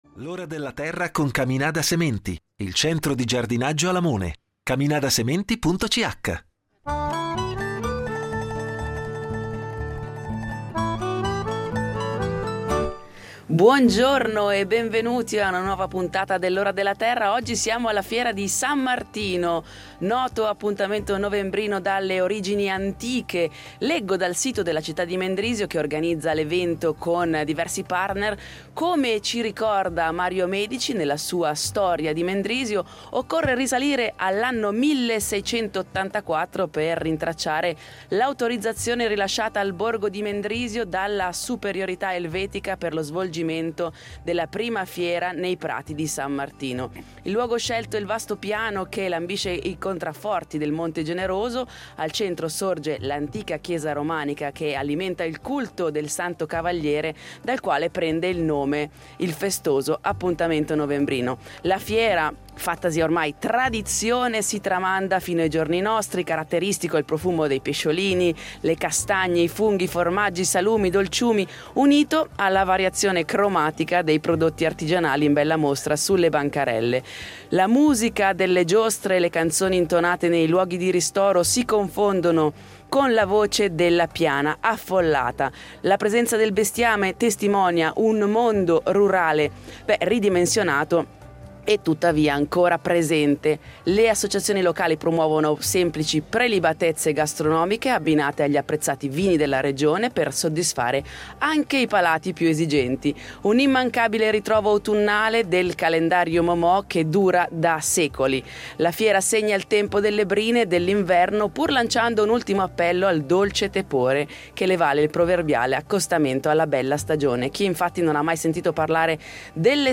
Torna puntuale come ogni anno la Fiera di San Martino a Mendrisio e noi ci saremo!
Non mancheranno gli esperti de L’Ora della Terra che dalle 09.00 alle 10.00 risponderanno alle domande del pubblico da casa e a quello presente alla fiera.